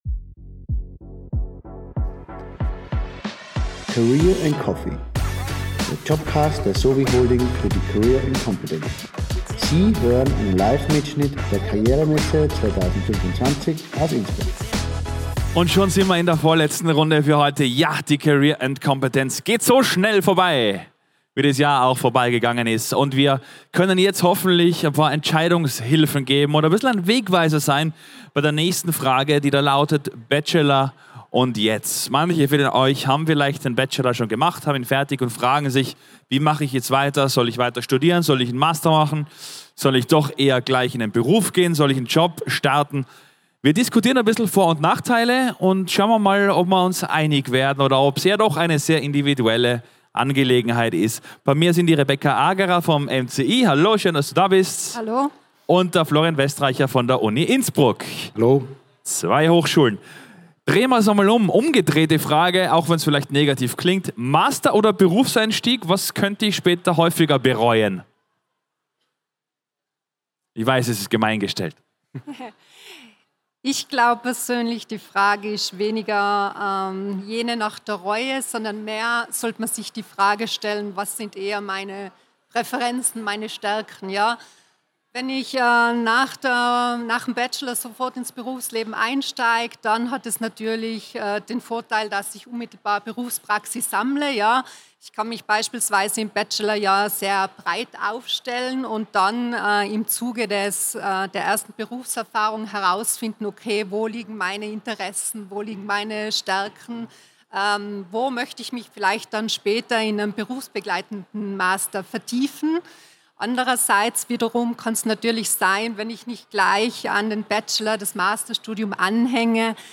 Livemitschnitt #10 von der career & competence am 14. Mai 2025 im Congress Innsbruck.